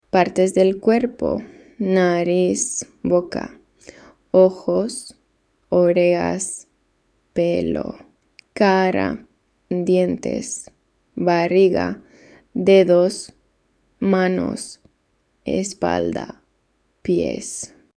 Lesson 3